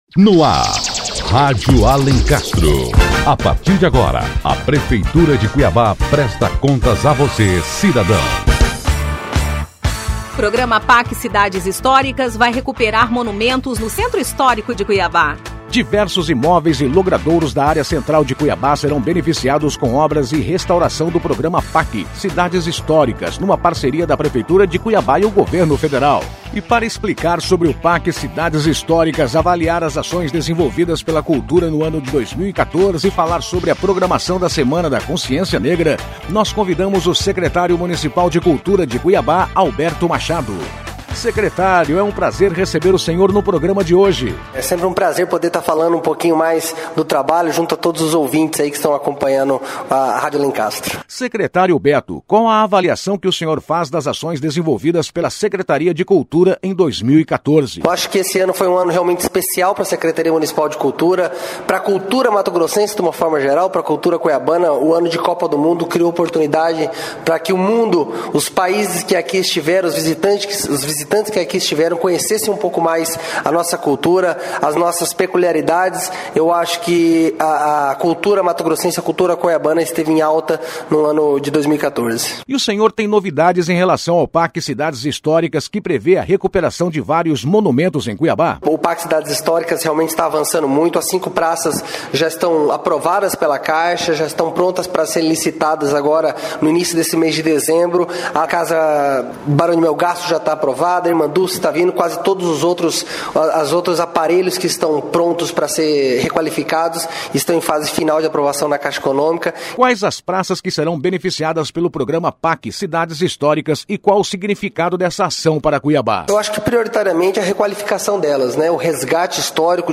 Notícias / 165º Programa 18 de Novembro de 2014 17h14 Imóveis da área central de Cuiabá serão restaurados pelo PAC Cidades Históricas Diversos imóveis e logradouros da área central de Cuiabá serão beneficiados com obras de restauração do programa Pac Cidades Históricas, uma parceria da Prefeitura de Cuiabá e Governo Federal. E para saber mais sobre as ações culturais da prefeitura, confira a entrevista com o Secretário Municipal de Cultura, Alberto Machado.